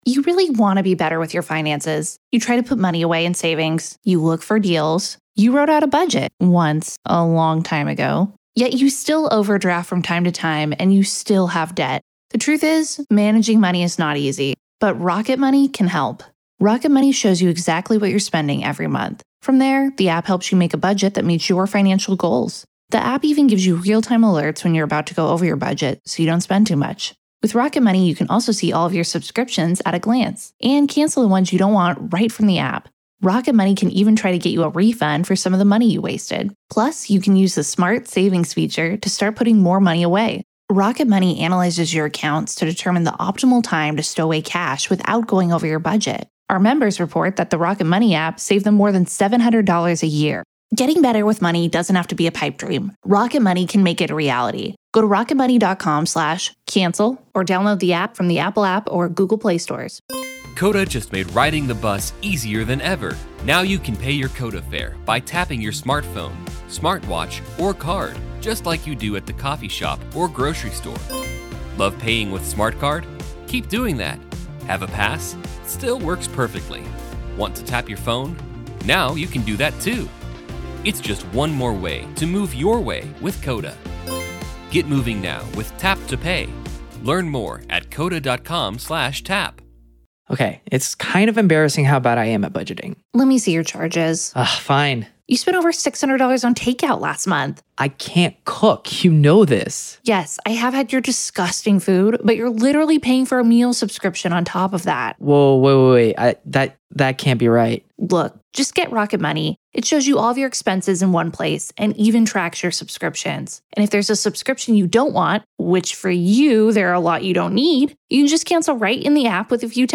LIVE COURTROOM COVERAGE — NO COMMENTARY